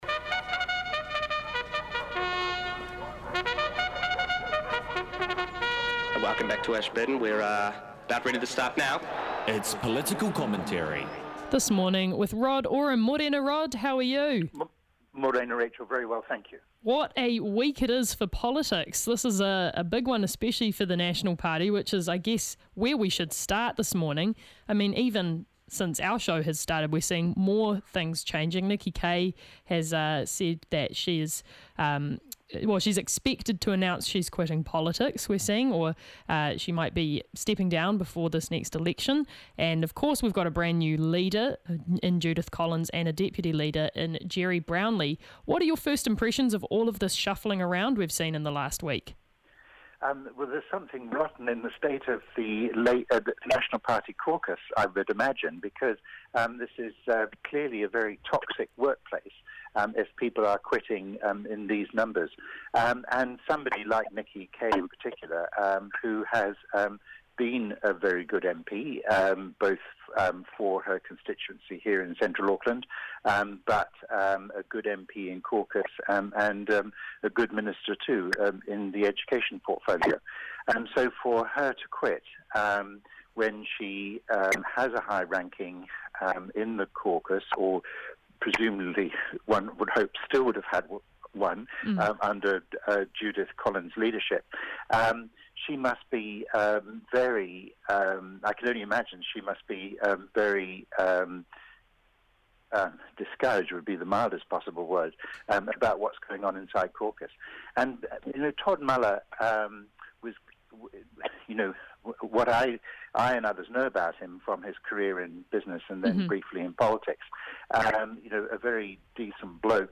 A rotating cast of the finest political minds offer up commentary on the big topics of the week.